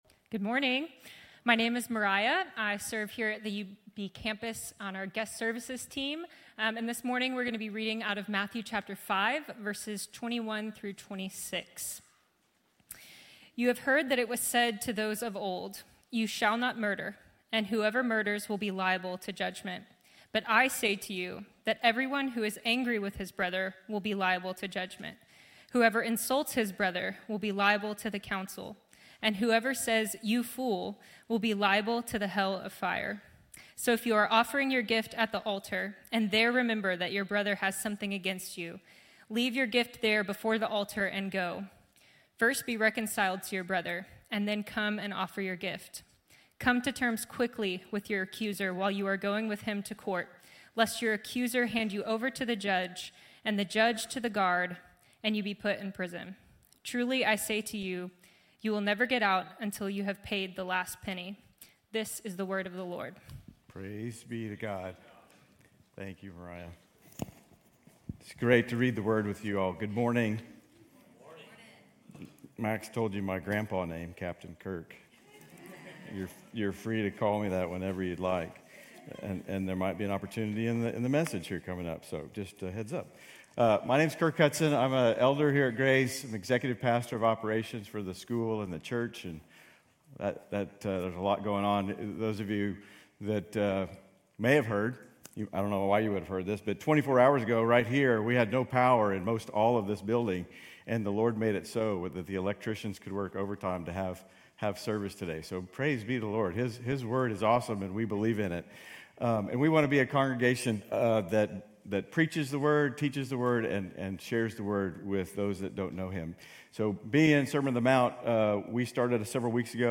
Grace Community Church University Blvd Campus Sermons 2_16 University Blvd Campus Feb 17 2025 | 00:27:39 1x 00:00 / 00:27:39 Subscribe Share RSS Feed Share Link Embed